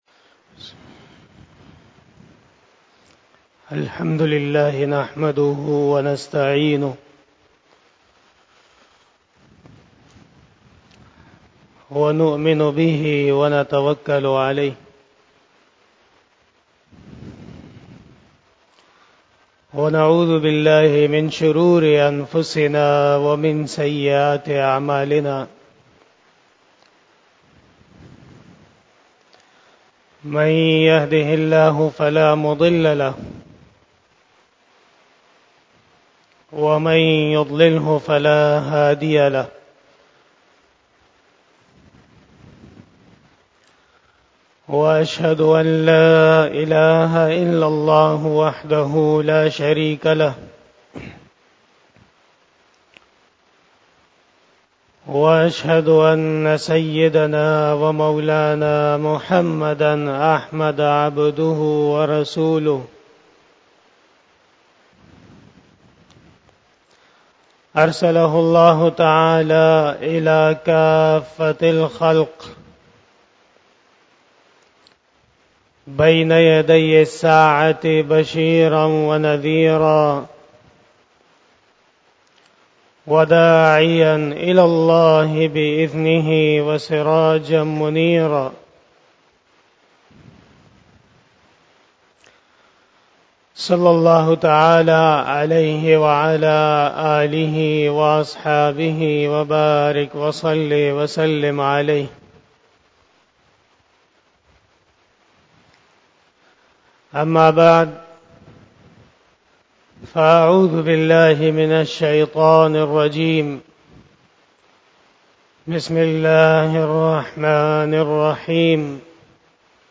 46 BAYAN E JUMA TUL MUBARAK 25 November 2022 (29 Rabi Us Sanil 1444H)
Khitab-e-Jummah